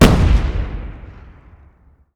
sound / wpn / grenade / explosion / explode
explode_00.LN65.pc.snd.wav